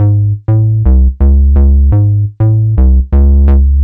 cch_bass_dark_125_G#m.wav